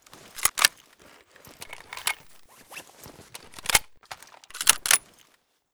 reload_empty.ogg